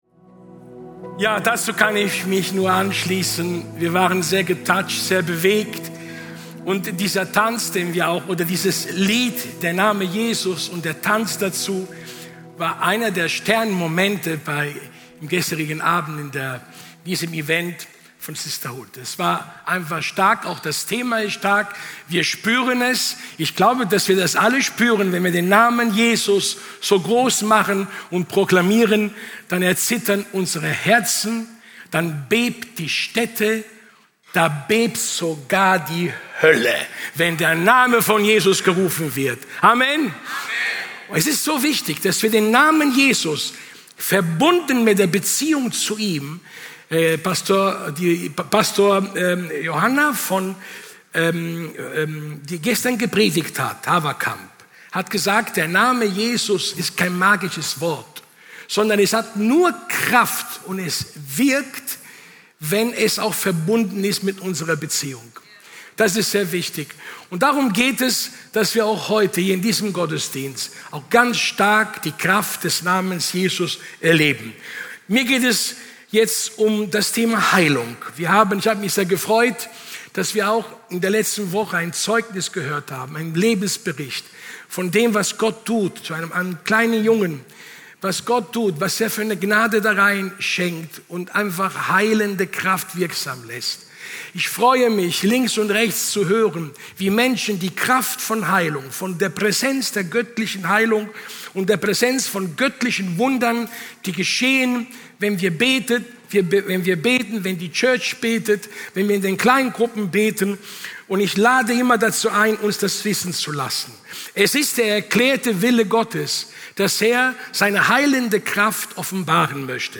Dienstart: Sonntag